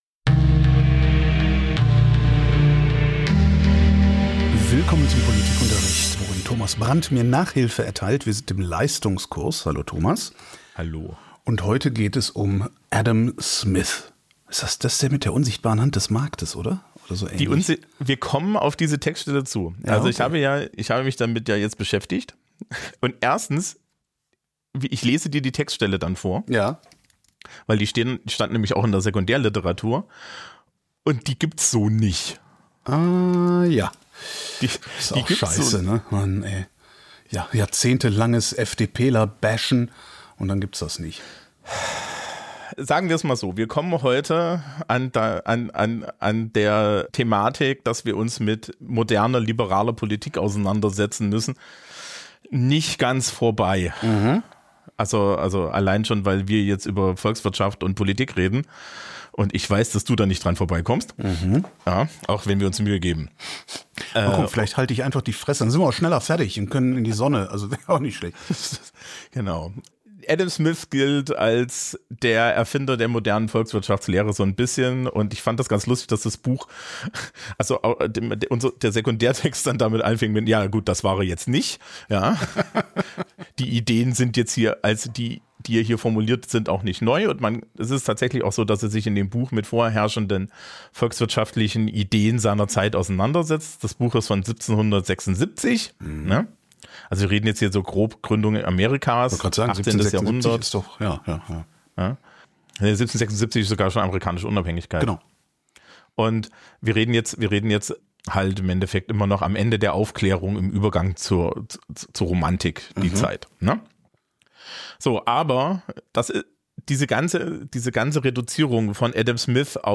Adam Smith 2025-10-21 08:28:26 UTC Die 13. Stunde unseres Leistungskurses Ideengeschichte Über den Nationalökonomen Adam Smith, der Ende des 18.